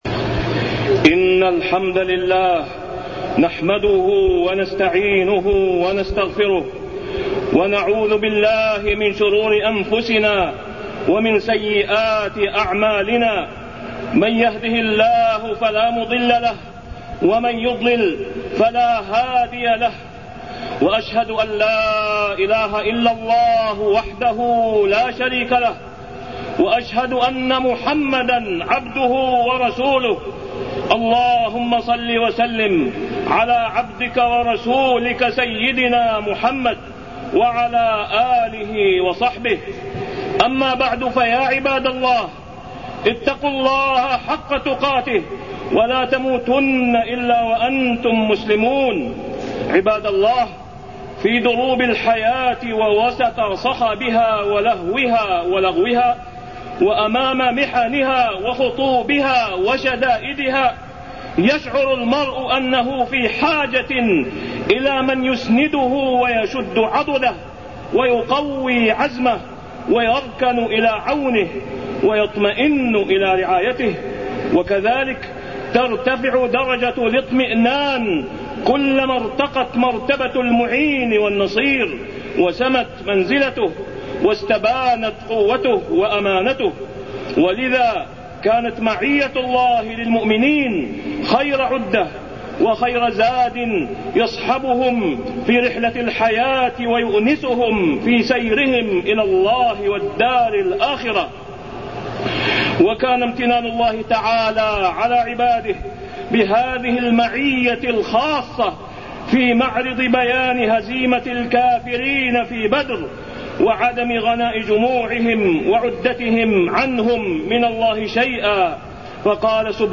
تاريخ النشر ١٧ جمادى الآخرة ١٤٢١ هـ المكان: المسجد الحرام الشيخ: فضيلة الشيخ د. أسامة بن عبدالله خياط فضيلة الشيخ د. أسامة بن عبدالله خياط معية الله للمؤمين